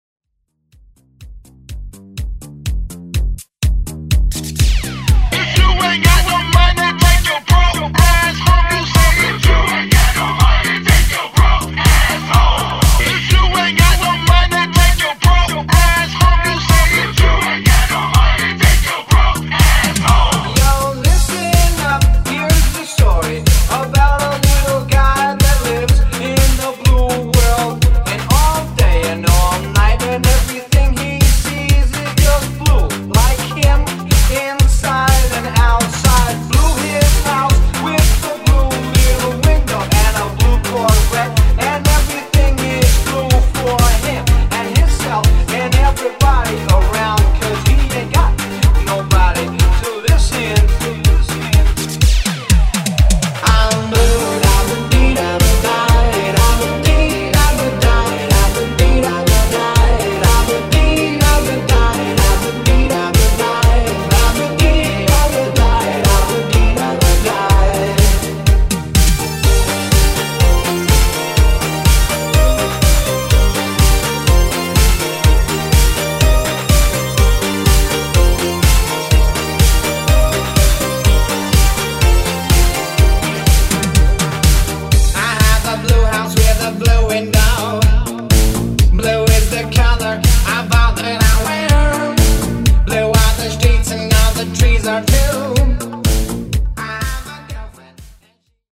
Genre: 70's
Clean BPM: 124 Time